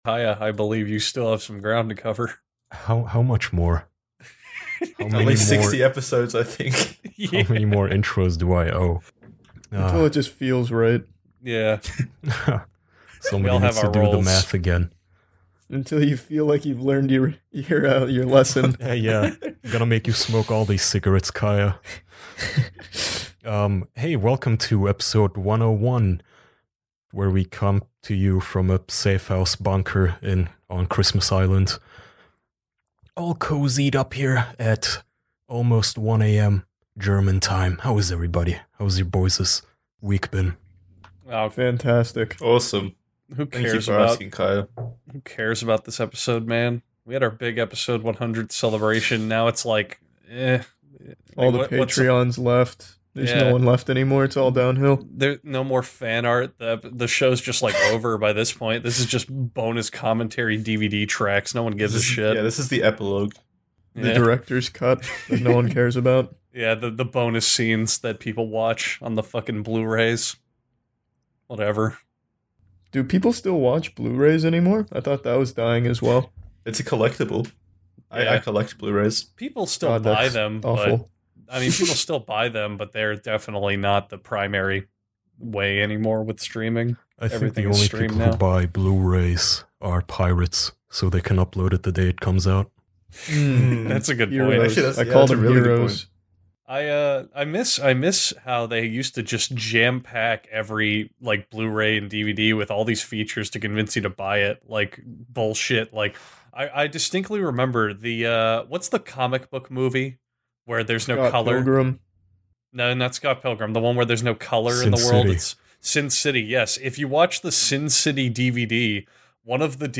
Four close man friends gather around to have a phone.